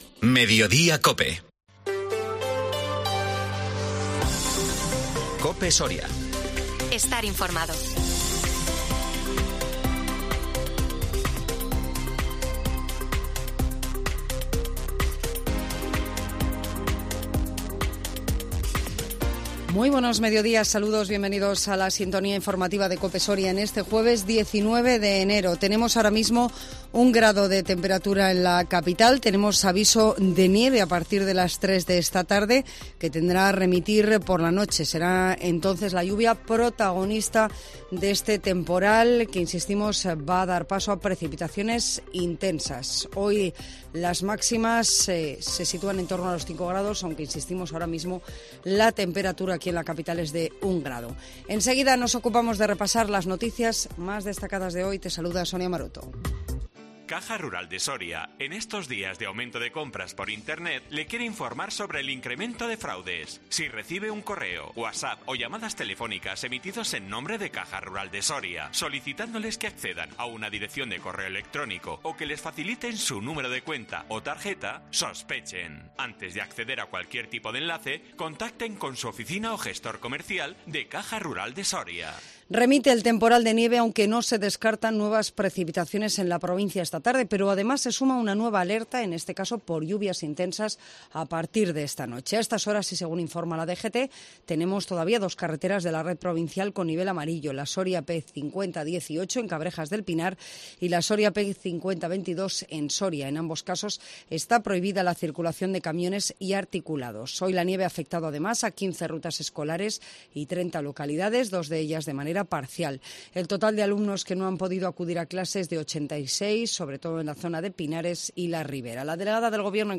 INFORMATIVO MEDIODÍA COPE SORIA 19 ENERO 2023